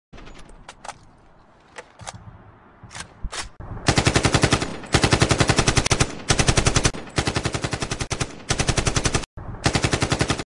silah-sesi-ak-47.mp3